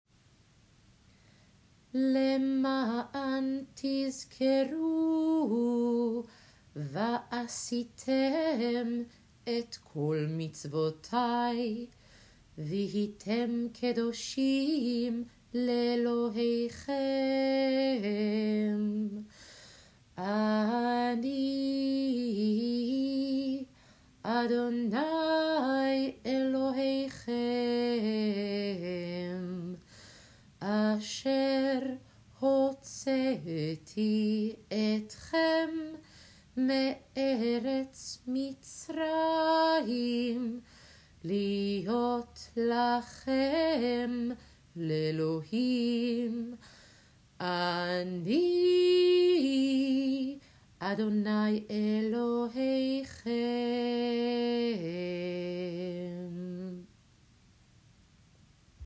L’ma’an (Chanted)